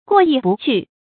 注音：ㄍㄨㄛˋ ㄧˋ ㄅㄨˋ ㄑㄩˋ
過意不去的讀法